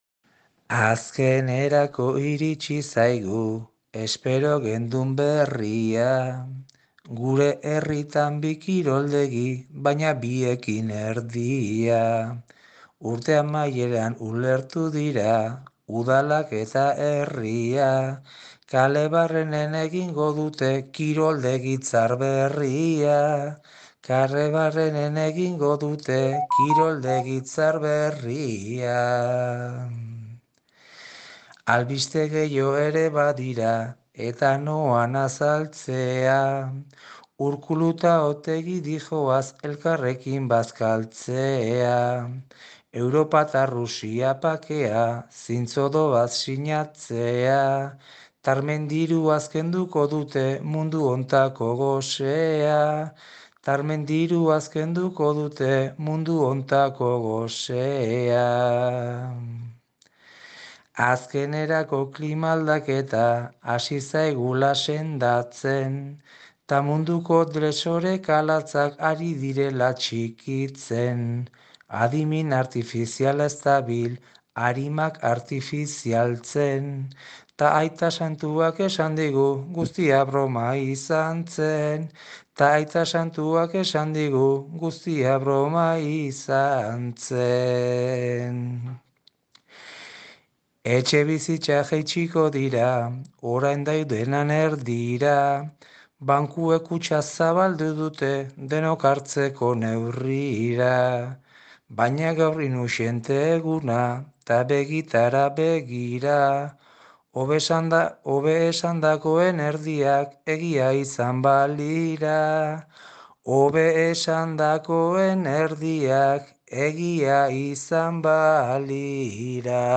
Doinua: Andre txarraren bentajak! (Txirrita) Azkenerako iritsi zaigu espero gendun berria!